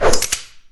q3rally/baseq3r/sound/weapons/change.ogg at master